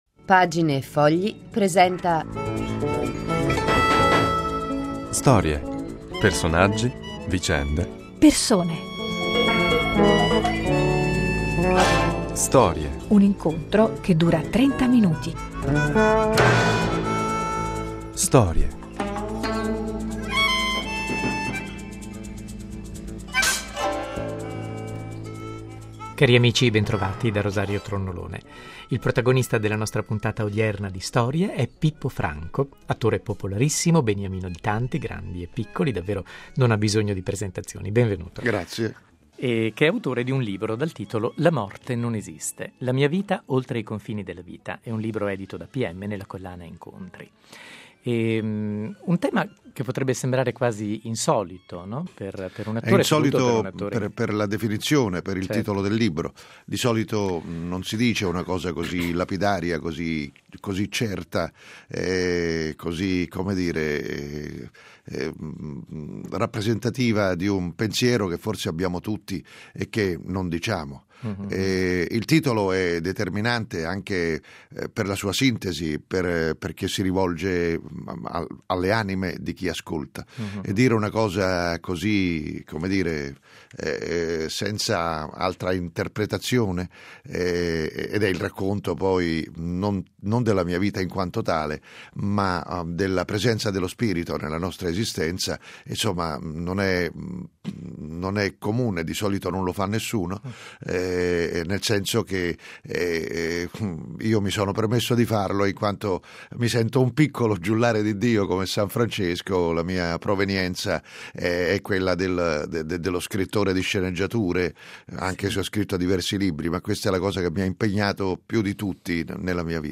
Lo conosciamo come efficace e poliedrico attore comico, ma oggi Pippo Franco, star dello storico locale romano il Bagaglino e protagonista di inesauribili varietà televisivi si racconta al microfono